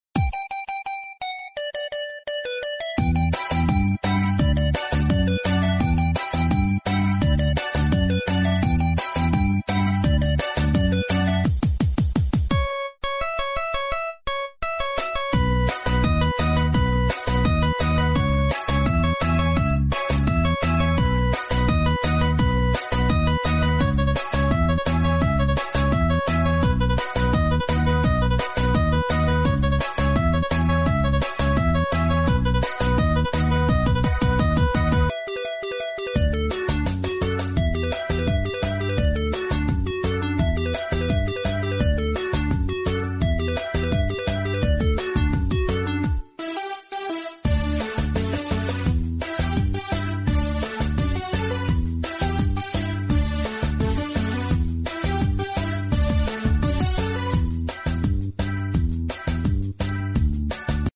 O melhor é a risada sound effects free download